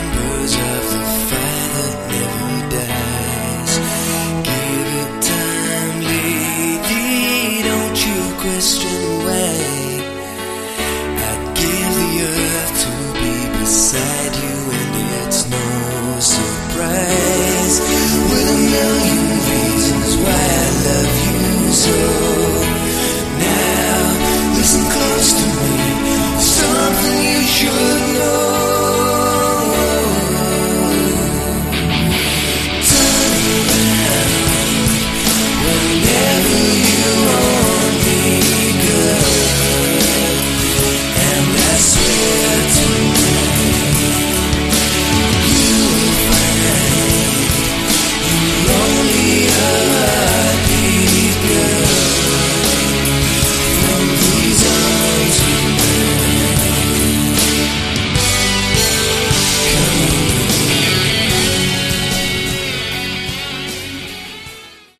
Category: AOR
lead and backing vocals
electric and acoustic guitars
drums, percussion
keyboards